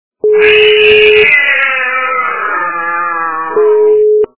» Звуки » Природа животные » Кот - Крики
При прослушивании Кот - Крики качество понижено и присутствуют гудки.
Звук Кот - Крики